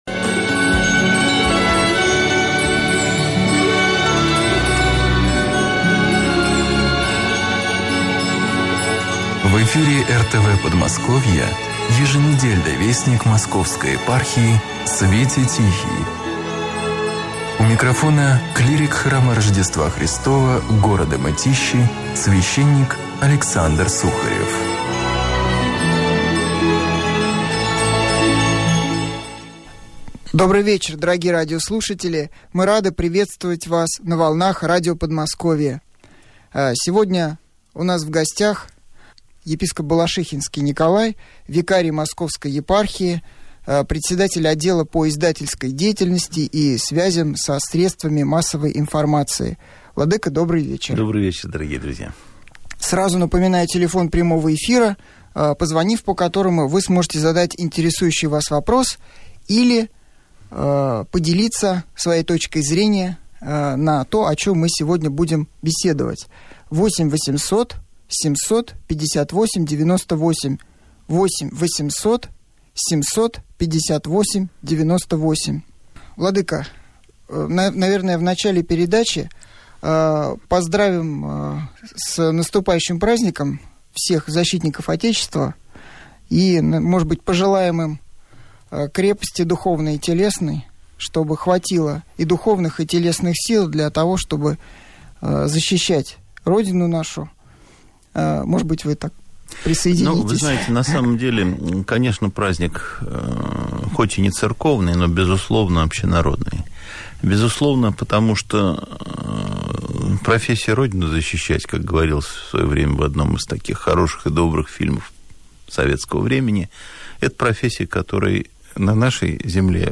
Прямой эфир с пресс-секретарем Московской епархии епископом Балашихинским Николаем, посвященный итогам Архиерейского Собора